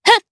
DarkFrey-Vox_Attack2_jp_b.wav